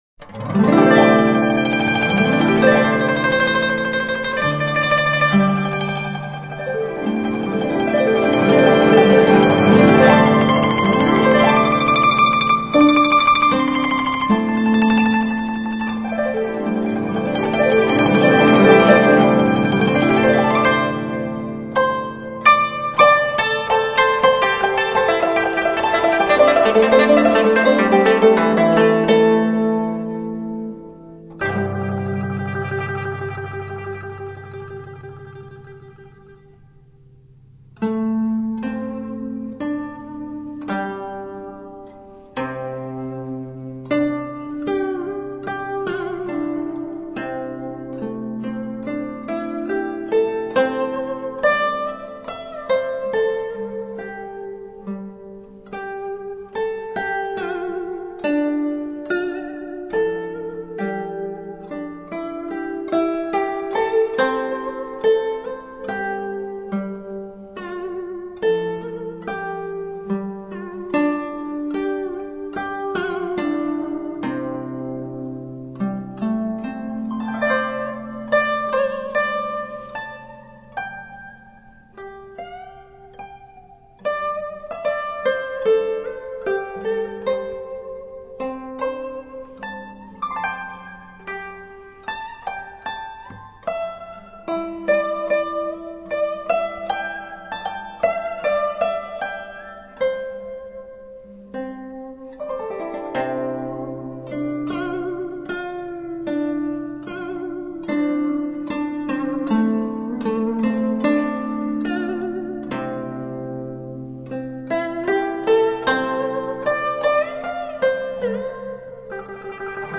古筝
手鼓
听她的这两张演奏专辑，筝技纯熟、风格洒脱，时而深婉抒情，时而柔美如歌；时而激昂感奋，时而纵横跌宕。